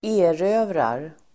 Uttal: [²'e:rö:vrar el. ²'ä:-]